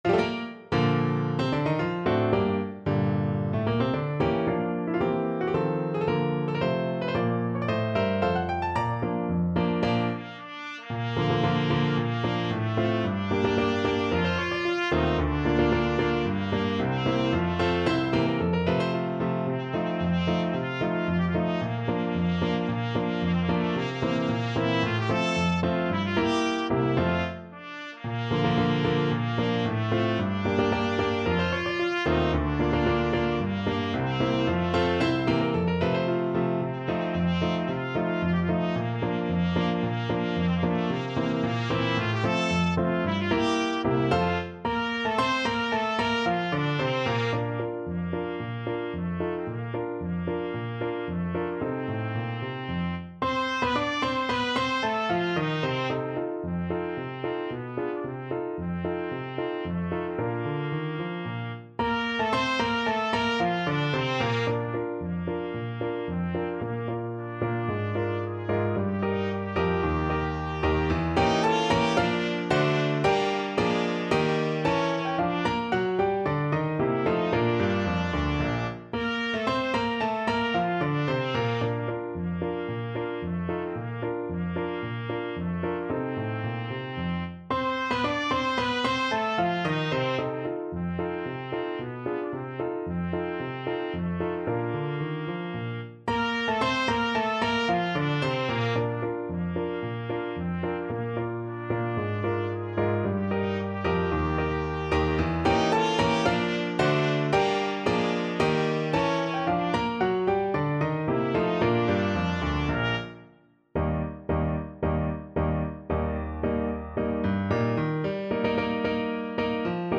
Free Sheet music for Trumpet Duet
Bb major (Sounding Pitch) C major (Trumpet in Bb) (View more Bb major Music for Trumpet Duet )
March =c.112